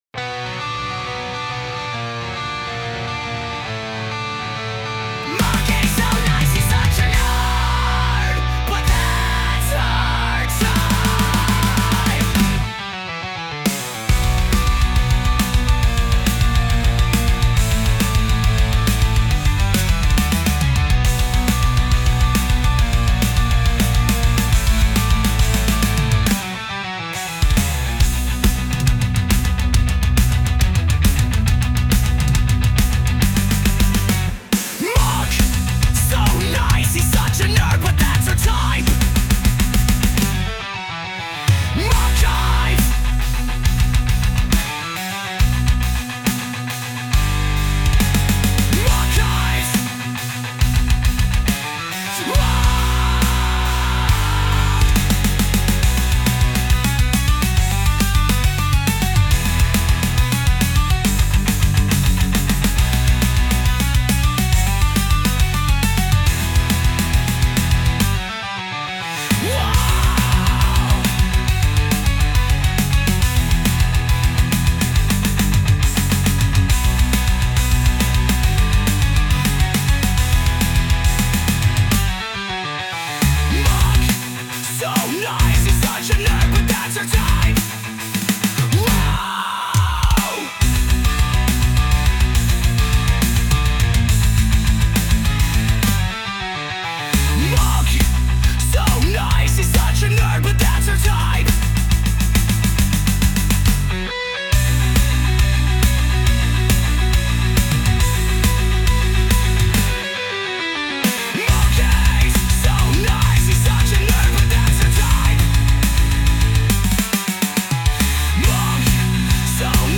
heavy metal screamo